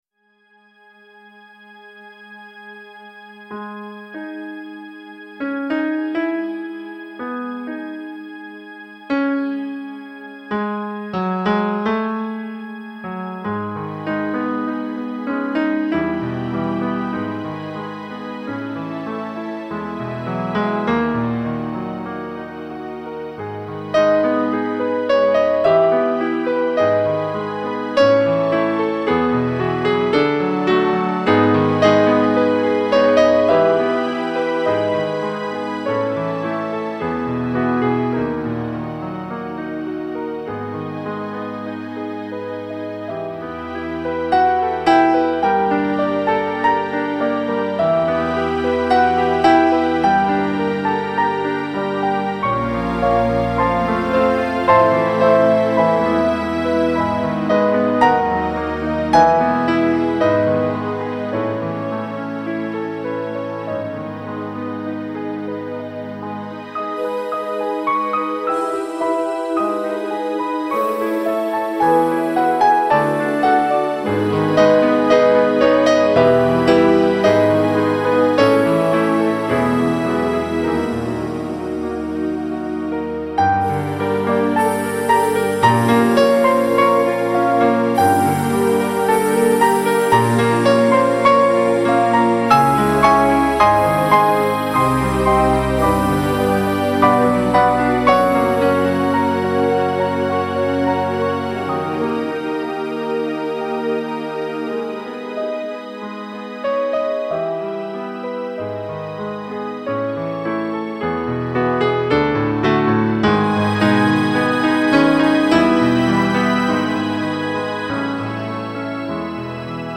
Genre: Piano / Classical / New Age